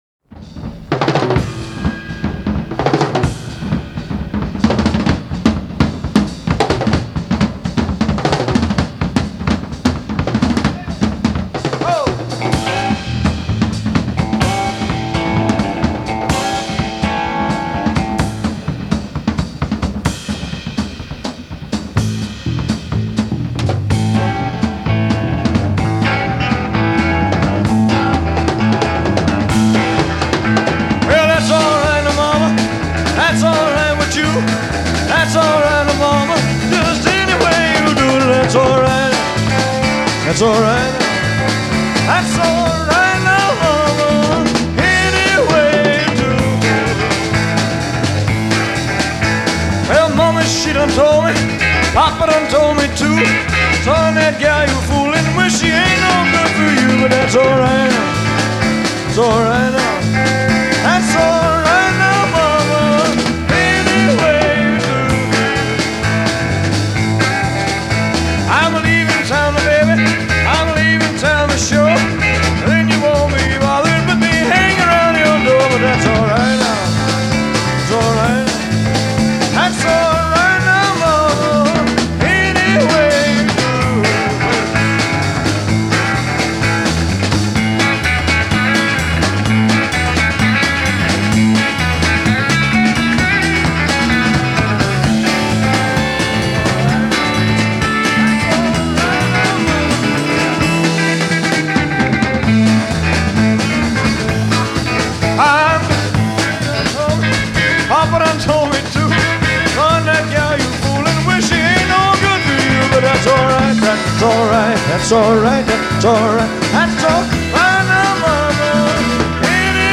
2-я Часть, 24 июля 1970, RCA Studio Hollywood.